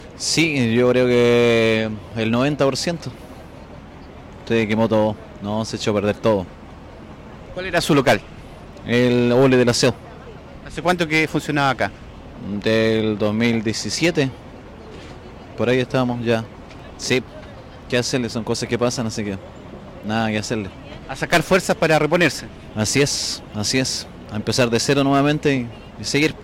cu-locatario-afectado-por-incendio.mp3